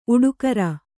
♪ uḍukara